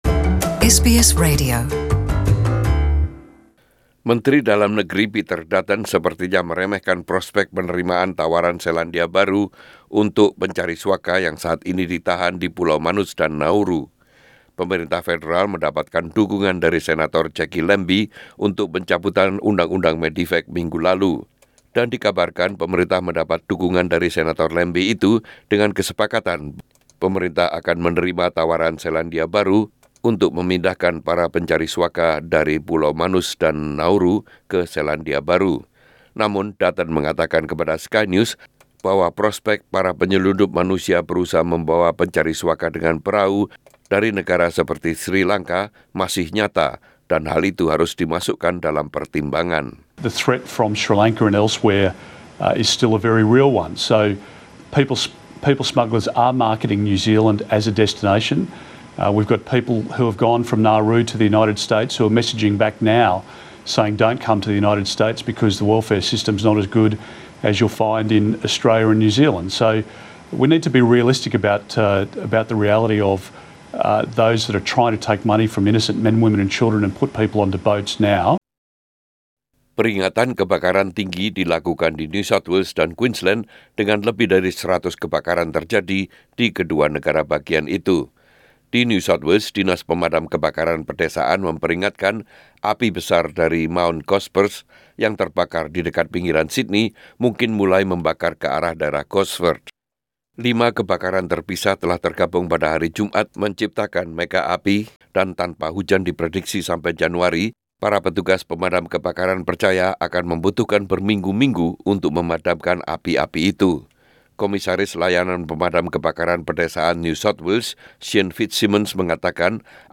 SBS Radio News in Indonesian - 8 December 2019